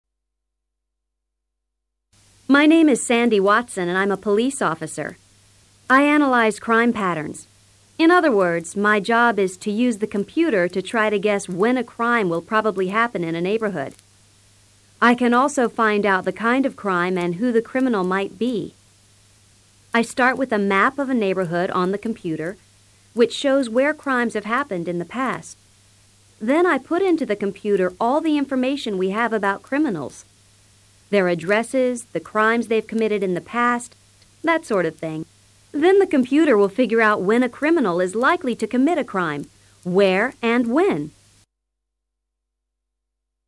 police officer